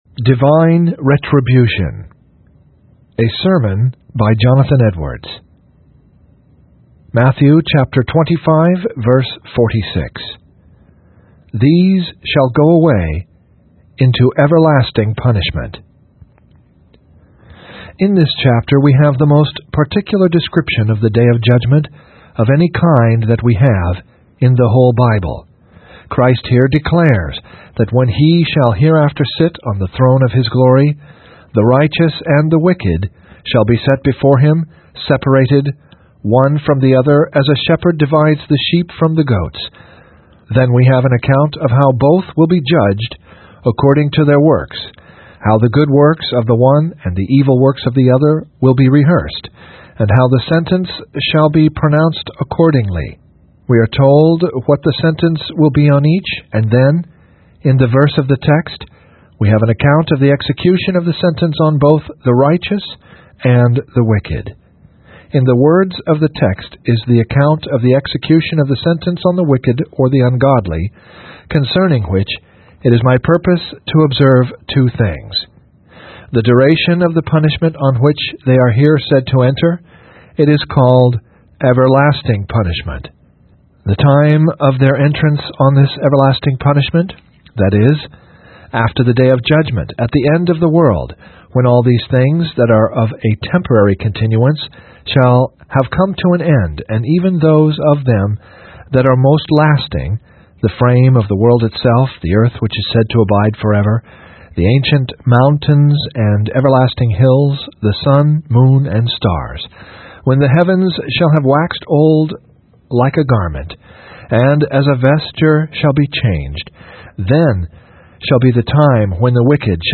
Divine Retribution (Reading) by Jonathan Edwards | SermonIndex